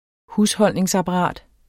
Udtale [ ˈhusˌhʌlˀneŋs- ]